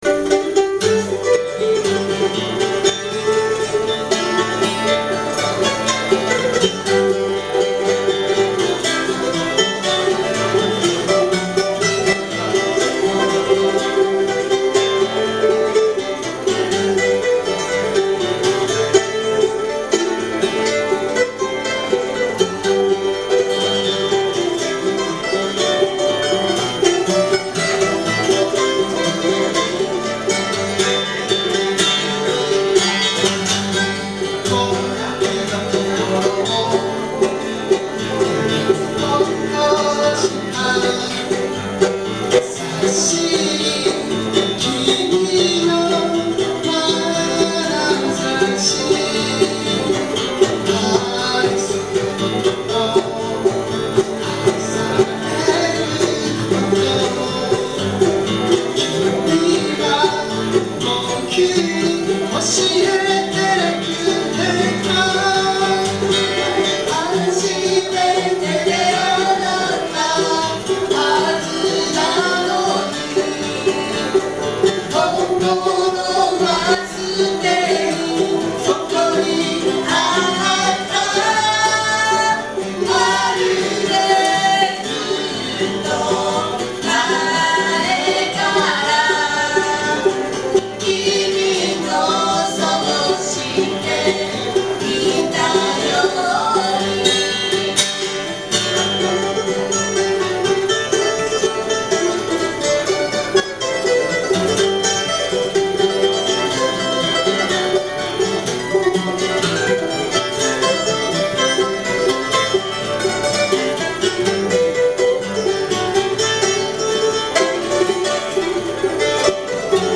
Bluegrass style Folk group
Key of G
この曲も最初はフォーク調だったのですが、ブルーグラススタイルにアレンジしてみました。
録音場所: 風に吹かれて(大森)
マンドリン
バンジョー
ボーカル、ギター